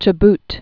(chə-bt, ch-)